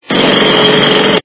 При прослушивании Звук - Отбойный молоток качество понижено и присутствуют гудки.
Звук Звук - Отбойный молоток